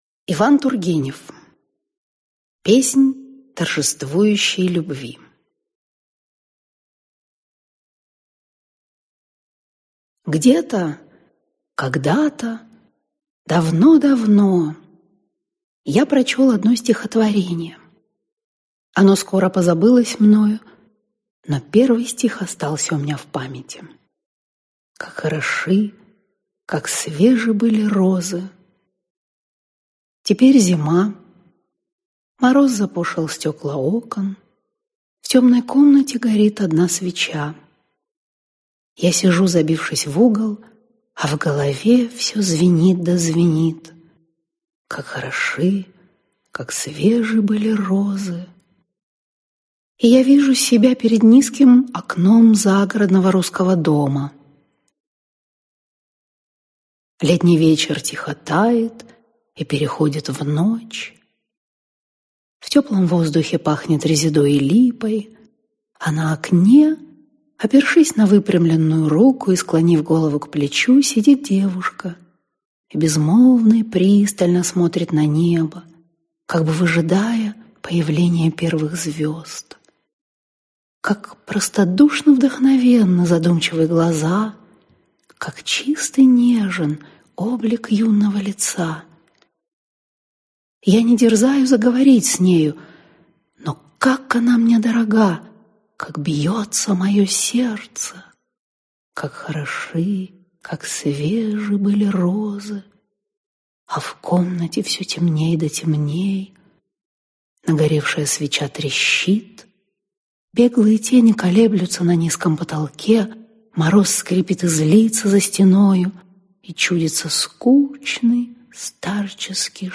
Аудиокнига Песнь торжествующей любви | Библиотека аудиокниг